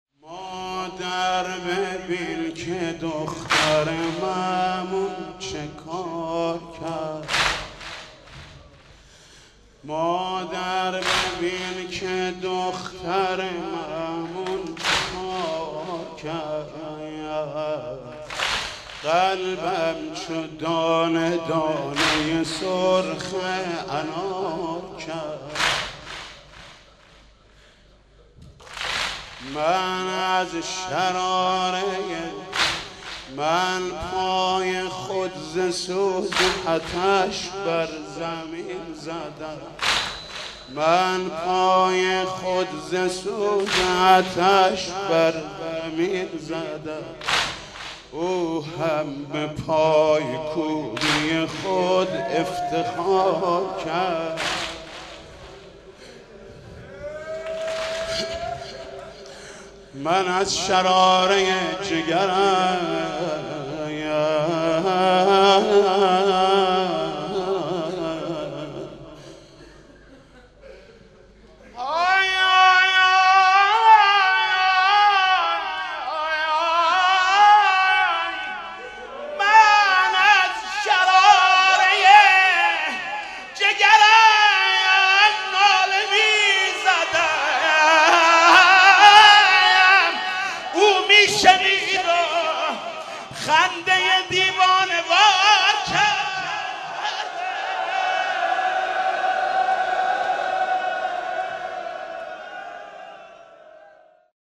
«شهادت امام جواد 1393» واحد: مادر ببین دختر مامون چه کار کرد